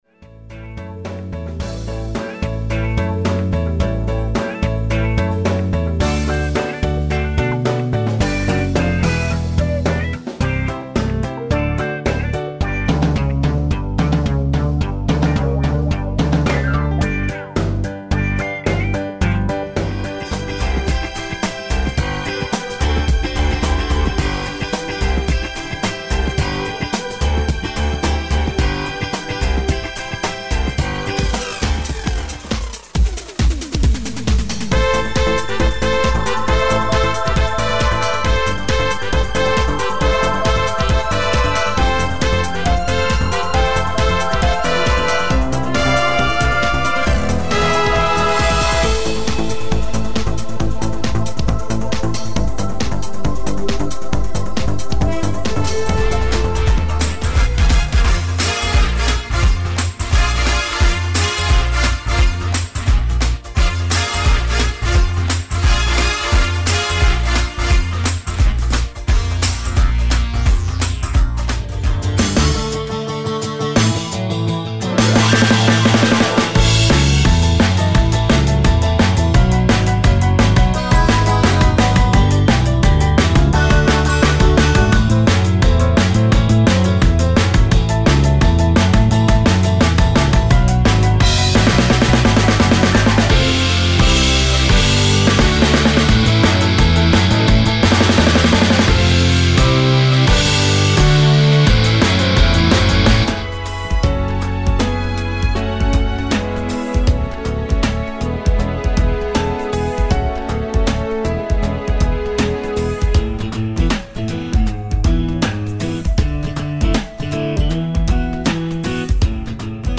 Full backing track arrangements
Backing Track Medley Sample